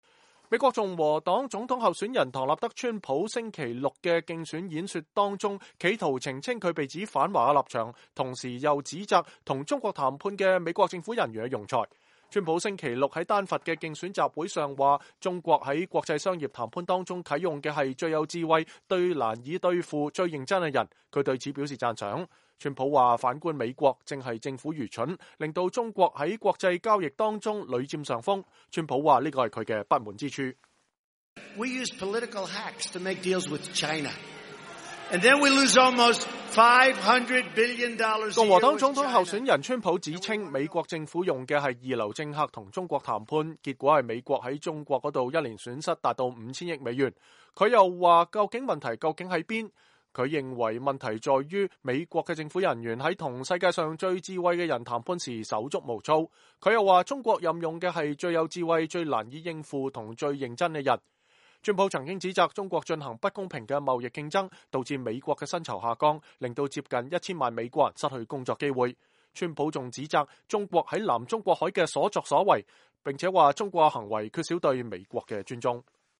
川普在11月5日的競選演講中澄清他被指“反華”的立場